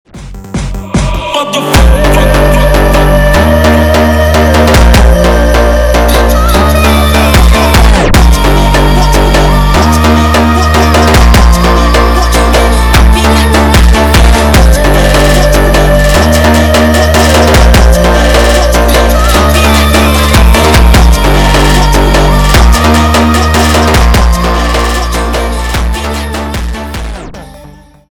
зарубежные клубные громкие